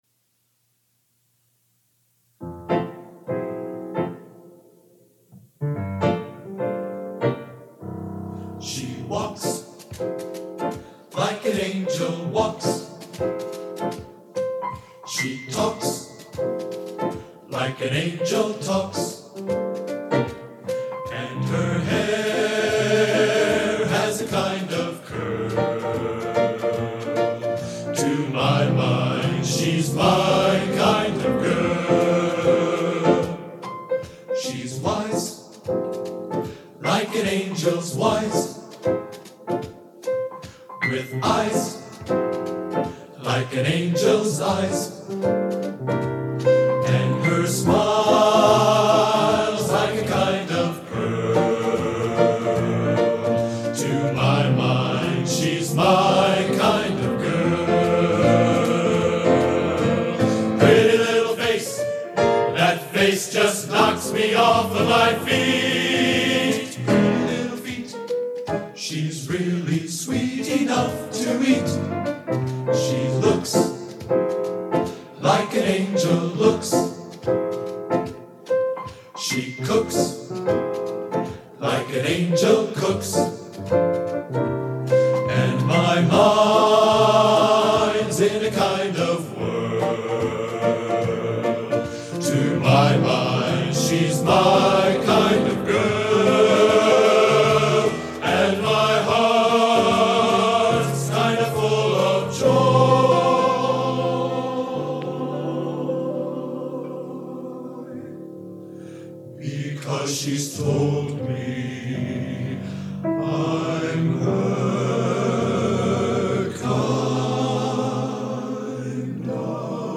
Genre: Popular / Standards Schmalz | Type: Studio Recording
Table Song